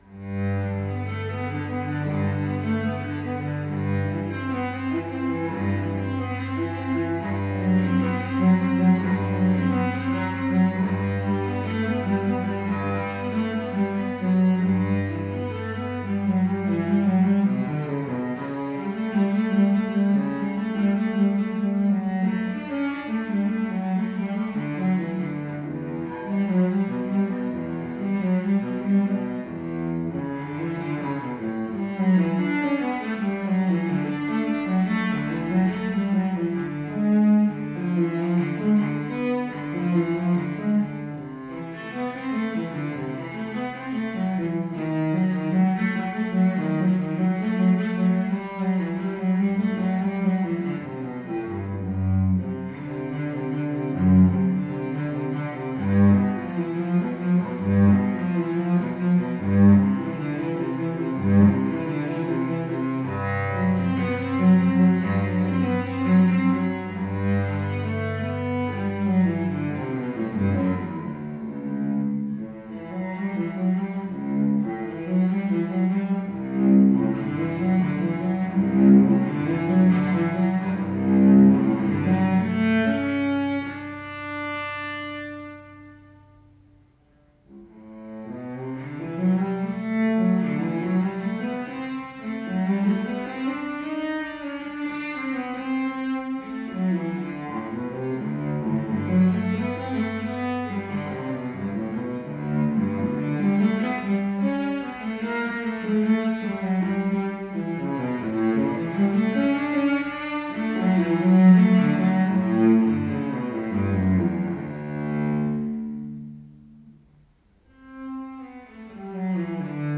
Violoncello solo